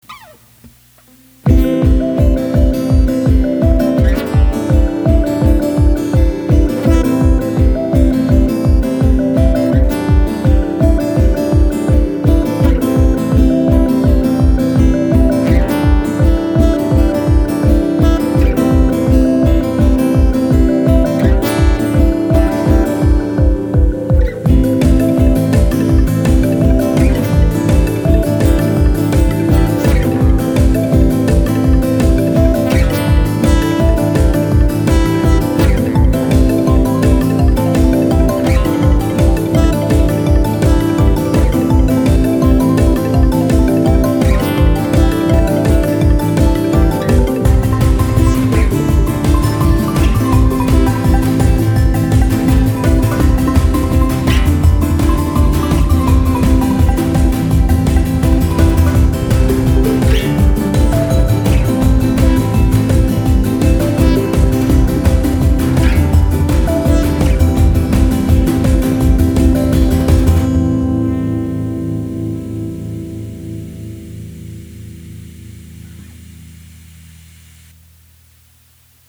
エレアコとベースは自前で録音。
他はサンプリング音源から組み立てただけ。